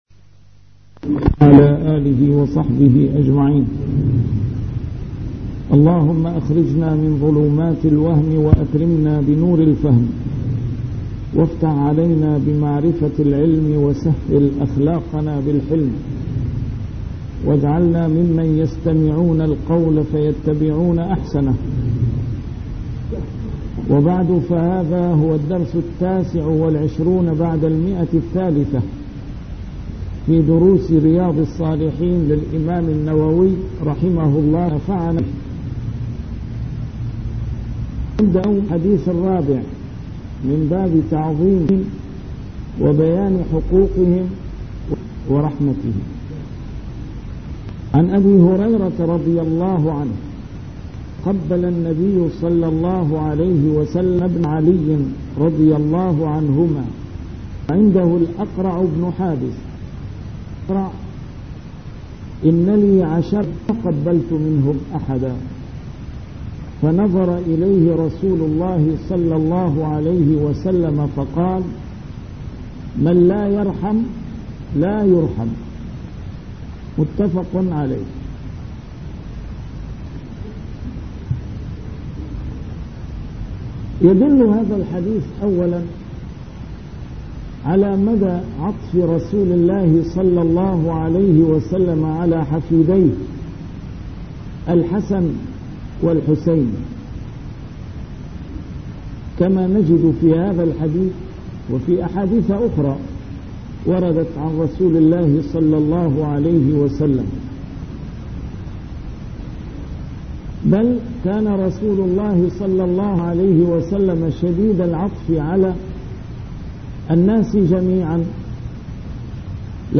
A MARTYR SCHOLAR: IMAM MUHAMMAD SAEED RAMADAN AL-BOUTI - الدروس العلمية - شرح كتاب رياض الصالحين - 329- شرح رياض الصالحين: تعظيم حرمات المسلمين